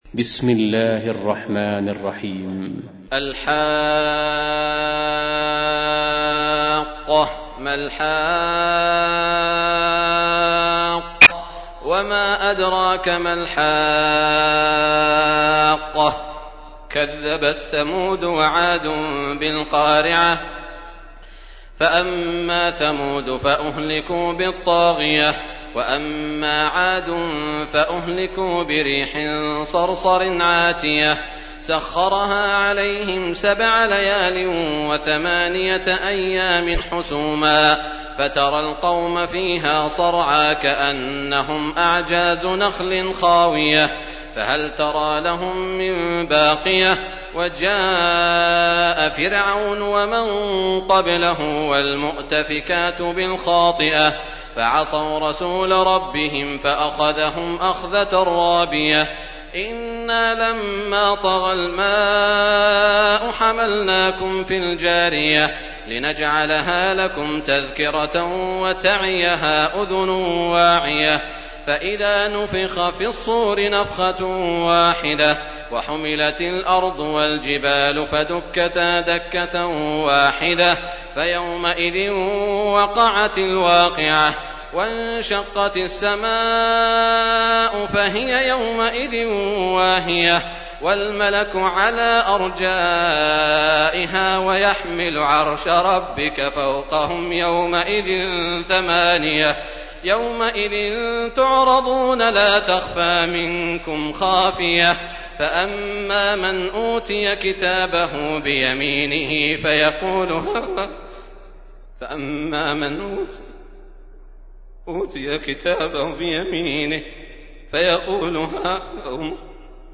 تحميل : 69. سورة الحاقة / القارئ سعود الشريم / القرآن الكريم / موقع يا حسين